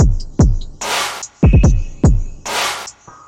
Tag: 73 bpm Rock Loops Drum Loops 566.53 KB wav Key : Unknown